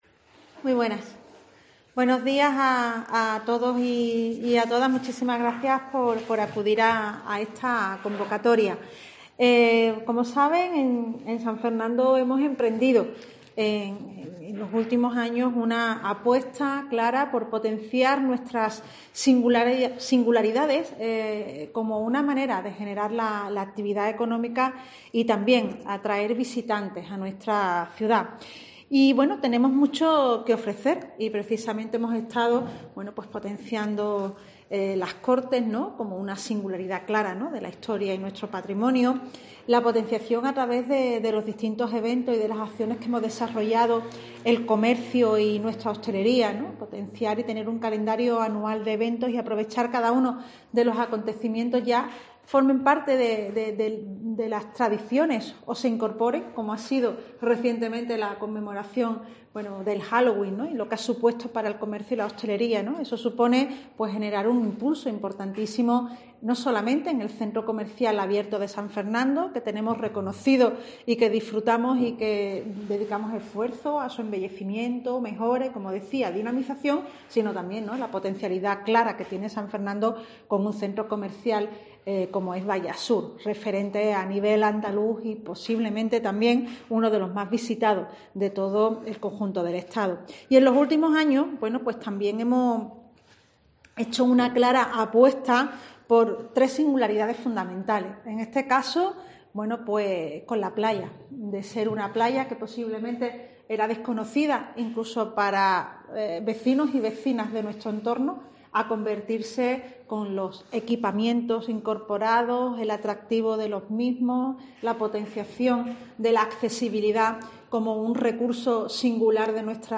La alcaldesa de San Fernando, Patricia Cavada, durante la presentación del Planetario